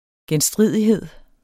Udtale [ gεnˈsdʁiˀðiˌheðˀ ]